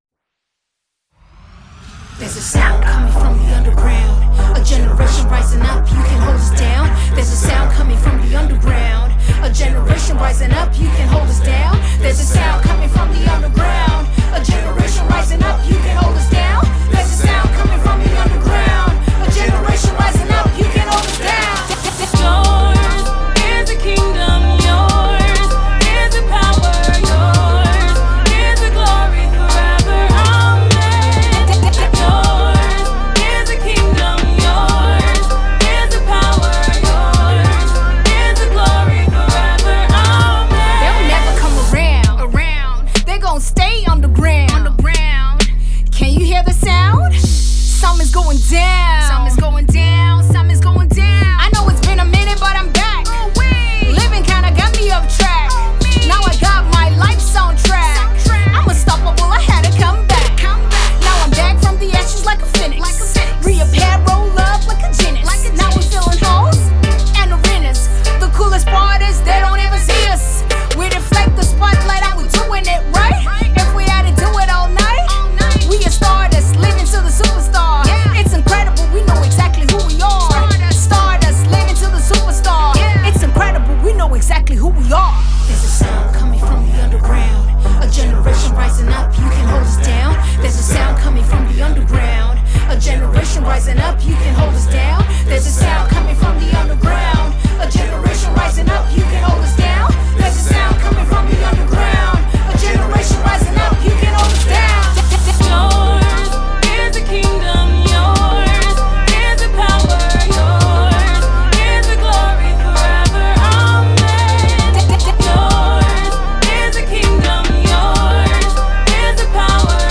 Nigerian female rapper
refined and subtle tune
contemporary gospo hip-hop tune